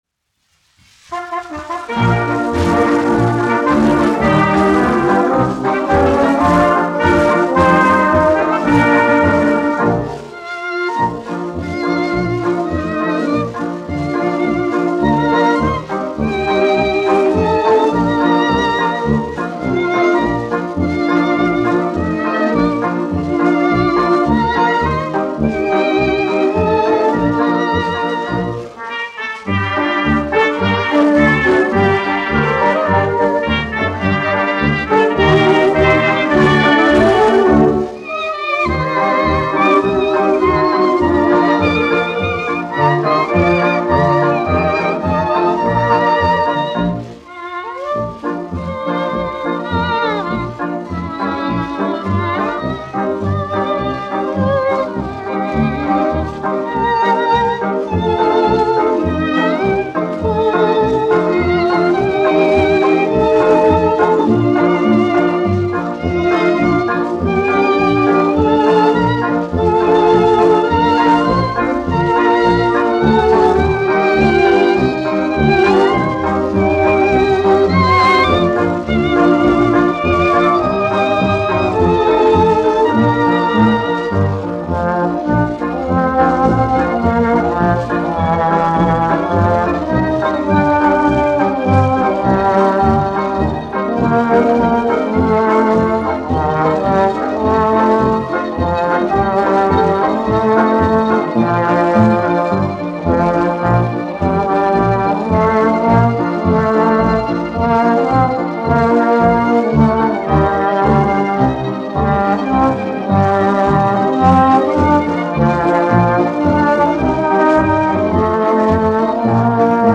1 skpl. : analogs, 78 apgr/min, mono ; 25 cm
Marši
Populārā instrumentālā mūzika
Skaņuplate